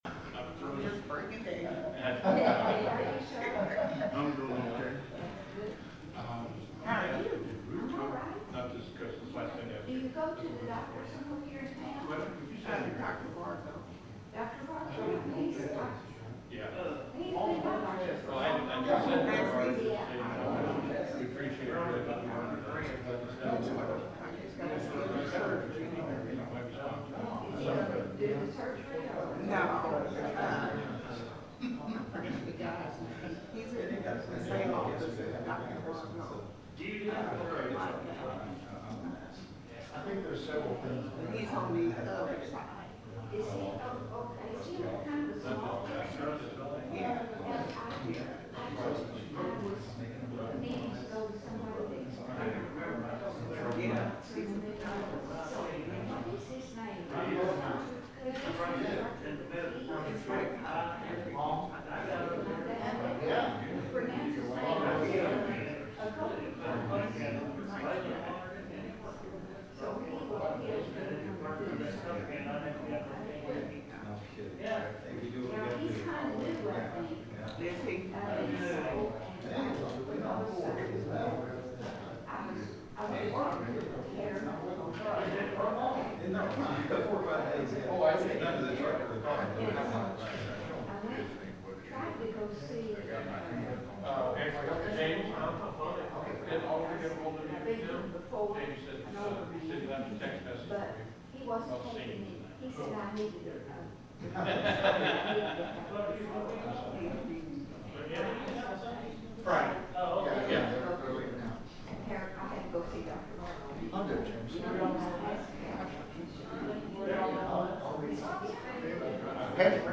The sermon is from our live stream on 7/30/2025